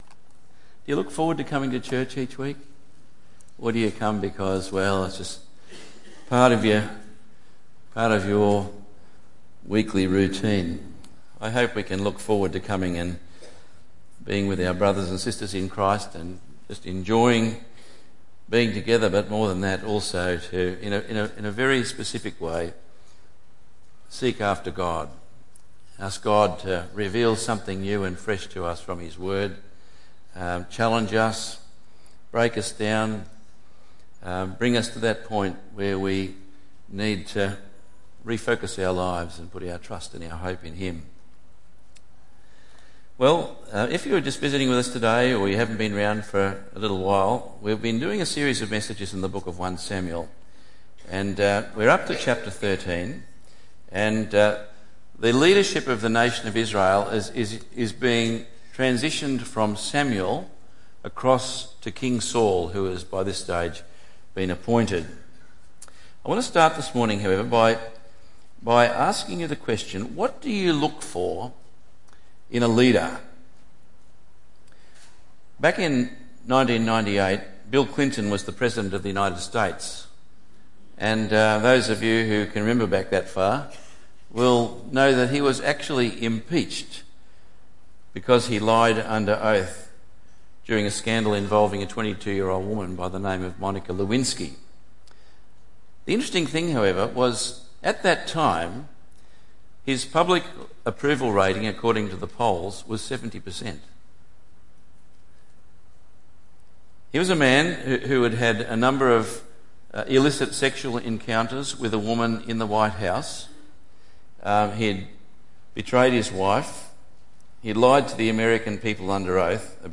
When our test comes will He find us faithful? 1 Samuel 13:1-15 Tagged with Sunday Morning